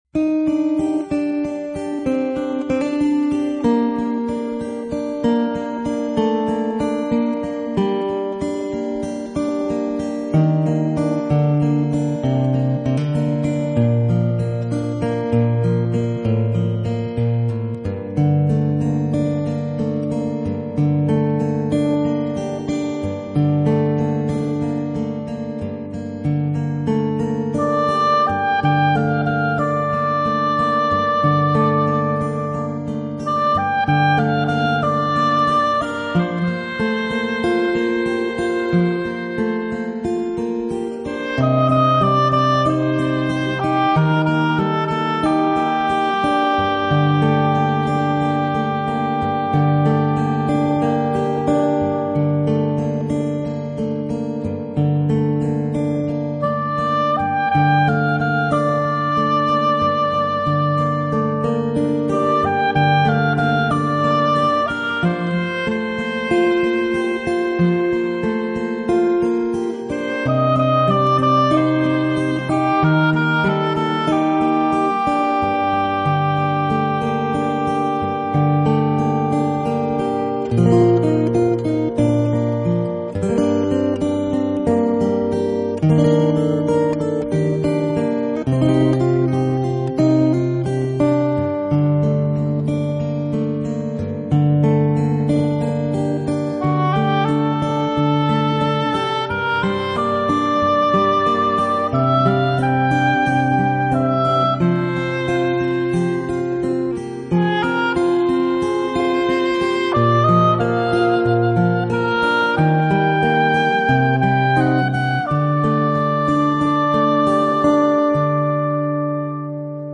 这些乐器的组合既罕见又奇特，其声音既不同寻常又出人意料。
吉他与木管乐器的纯熟配合展 现出的音乐气势宏大、浑然天成。